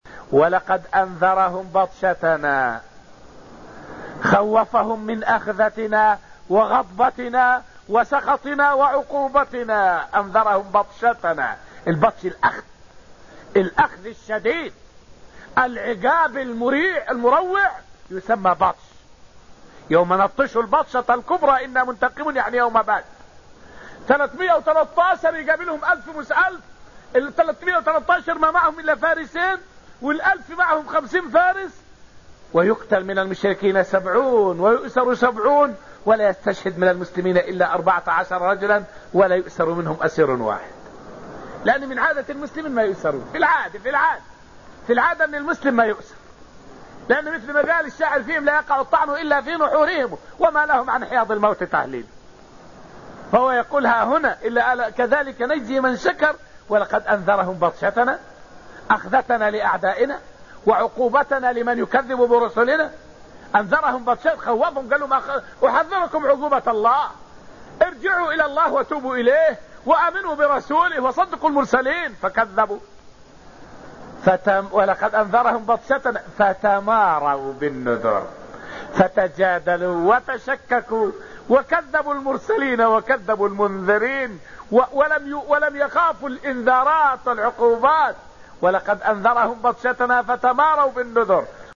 فائدة من الدرس السابع من دروس تفسير سورة القمر والتي ألقيت في المسجد النبوي الشريف حول تحذير الأنبياء لأقوامهم من بطش الله.